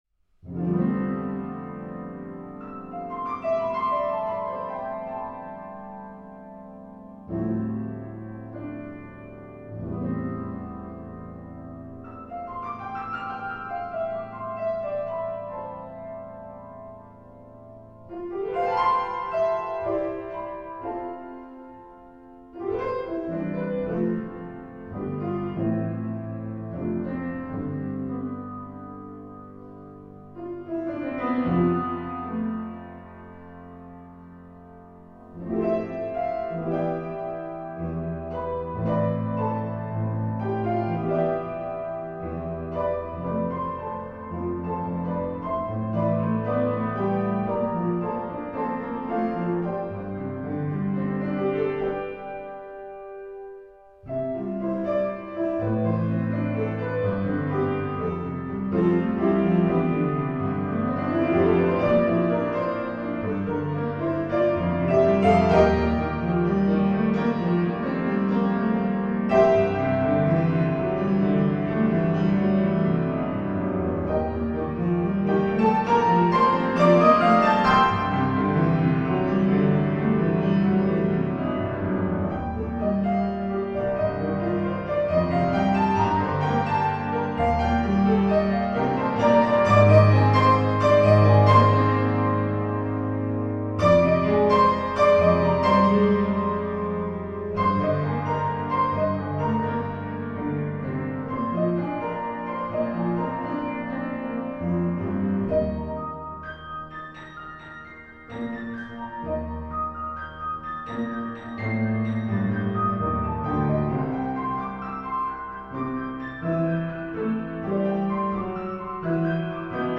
Genre: Solo Piano
Solo Piano